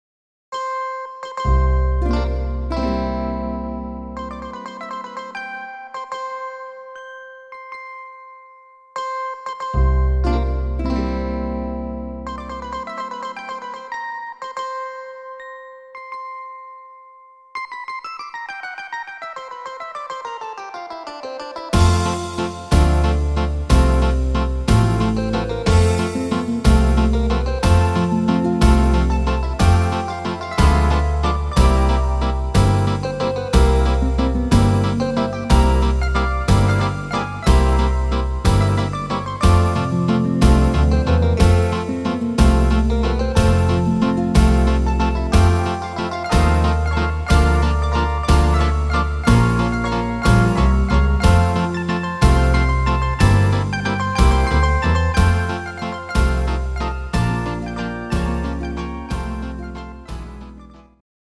instr. Zither